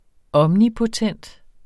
Udtale [ ˈʌmni- ]